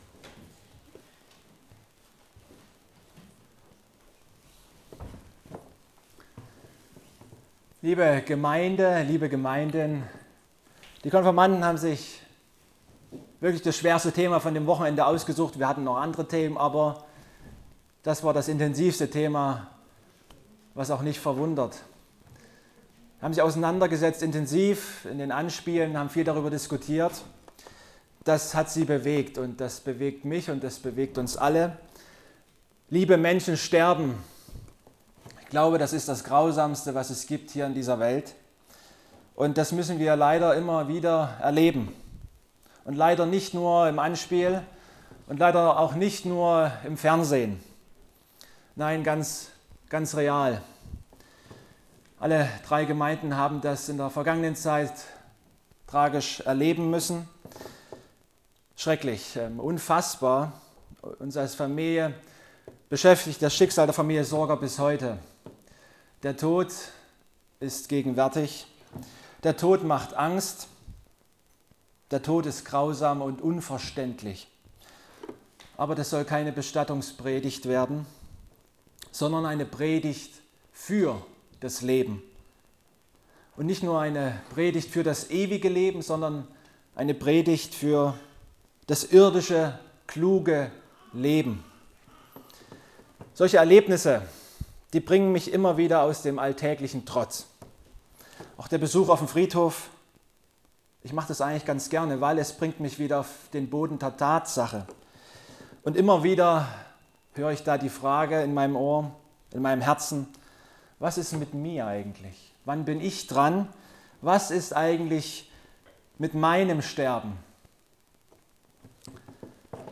Passage: Jakobus 4,13-16 & Psalm 90,12 Gottesdienstart: Gemeinsamer Gottesdienst Vorstellung der Konfirmanden « Keine Rück-Sicht Ostern